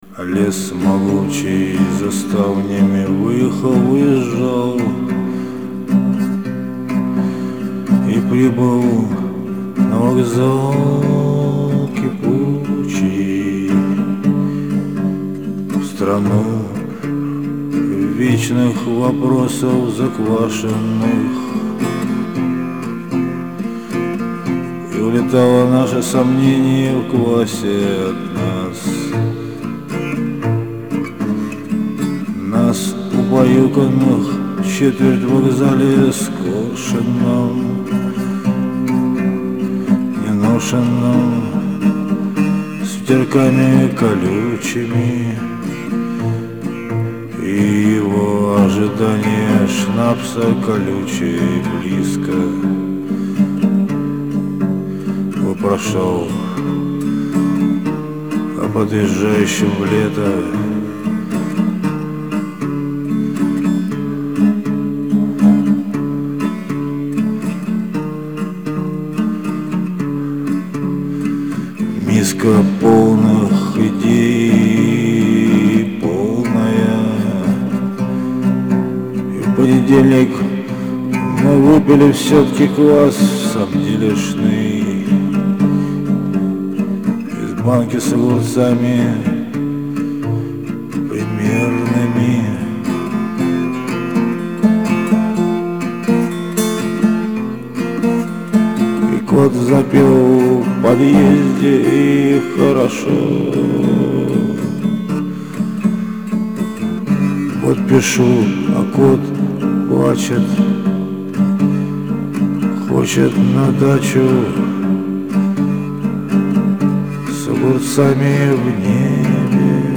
во, спел,
теперь все песни будут новые лучше качество, добавил коечто в усилитель, старое изобретение